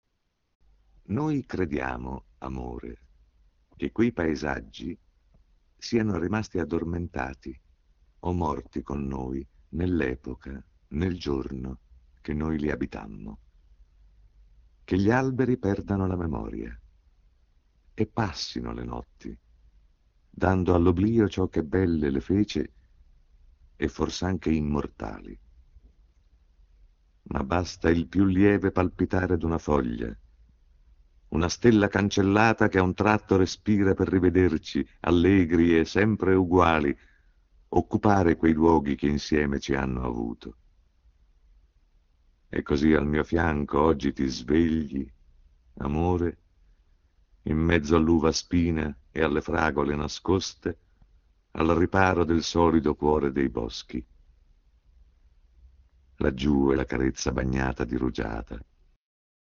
Per voi le più prestigiose interpretazioni del Maestro Arnoldo Foà